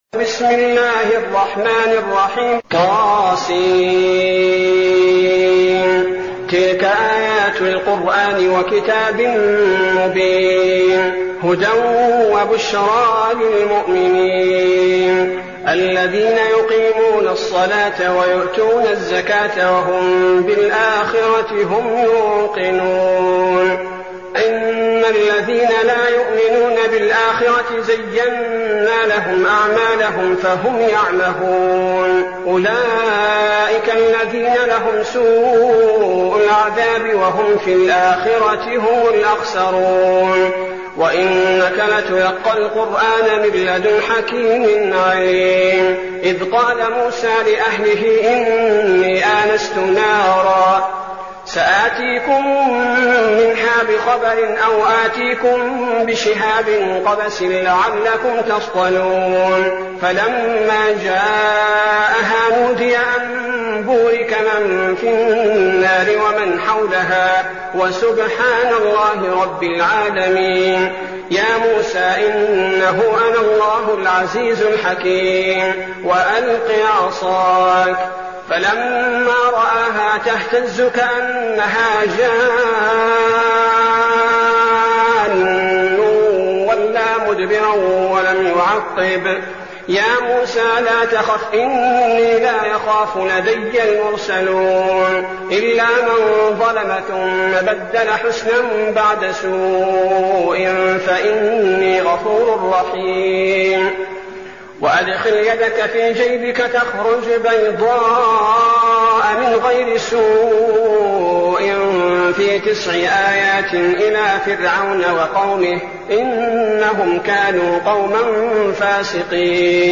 المكان: المسجد النبوي الشيخ: فضيلة الشيخ عبدالباري الثبيتي فضيلة الشيخ عبدالباري الثبيتي النمل